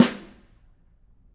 Snare2-HitSN_v5_rr1_Sum.wav